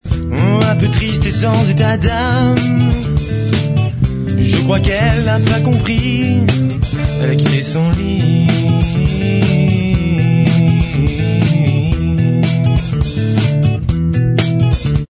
chanson influences pop